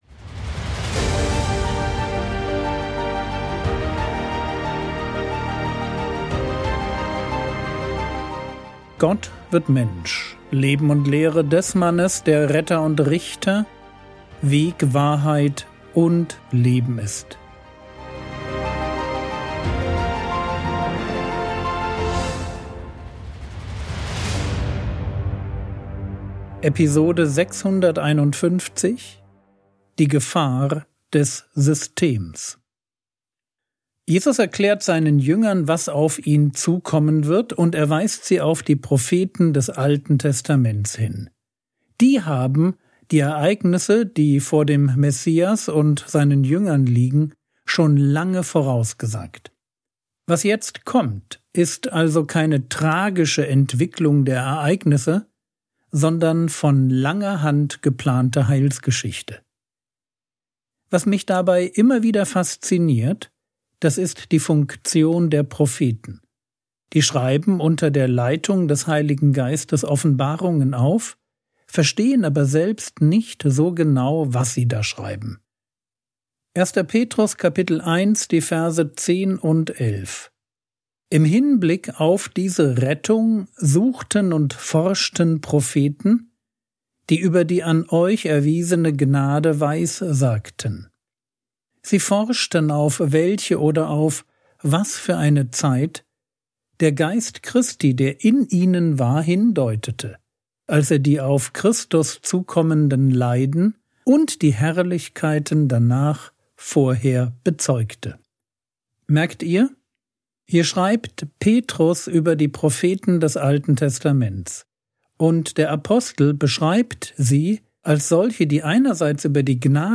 Episode 651 | Jesu Leben und Lehre ~ Frogwords Mini-Predigt Podcast